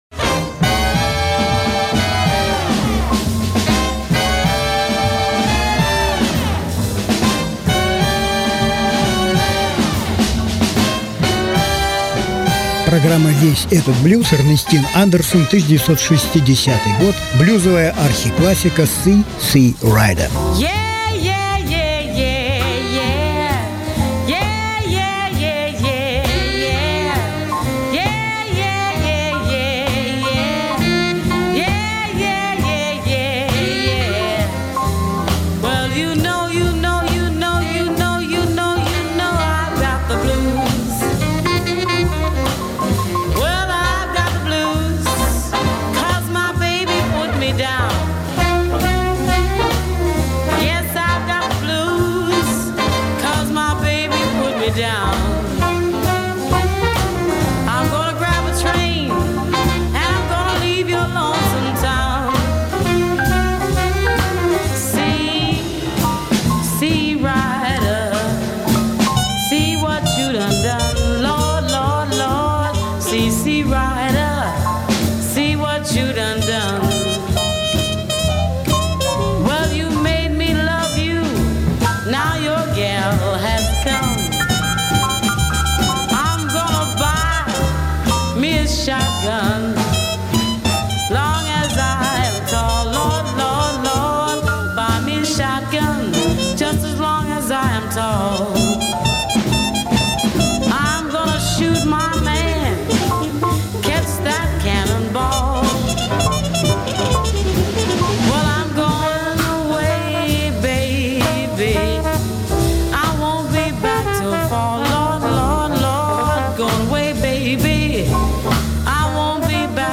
Жанр: Блюзы СОДЕРЖАНИЕ 12.11.2018 1.